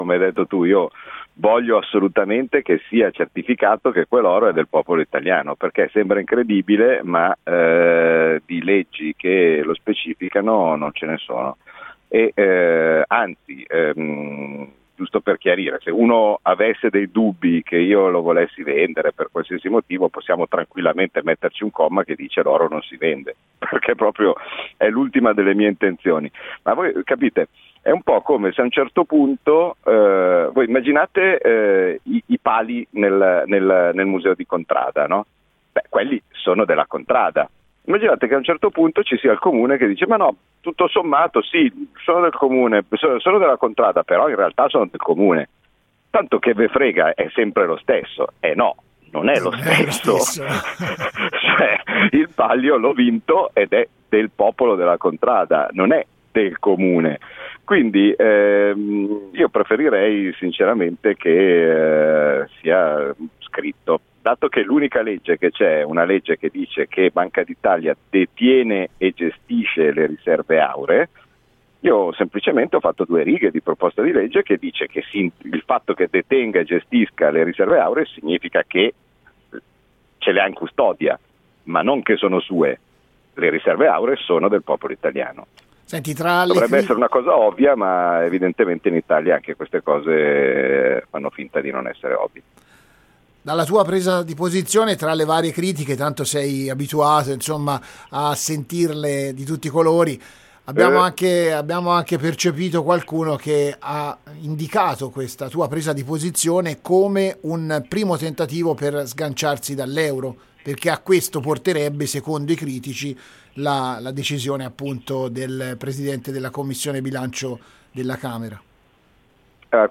Sta facendo molto discutere l’iniziativa del presidente della commissione bilancio della Camera, il senese d’adozione Claudio Borghi, che vuole che venga certificato a chi materialmente appartengono le riserve auree dello stato italiano. “Ma il governo non ha nessuna intenzione di vendere l’oro del Paese” spiega ai nostri microfoni Borghi, facendo un paragone con i Drappelloni del Palio di Siena.